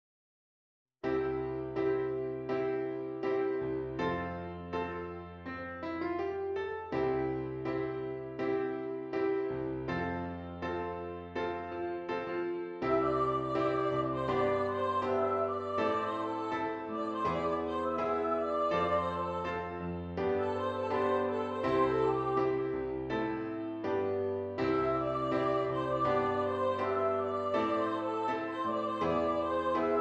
C Major
Andante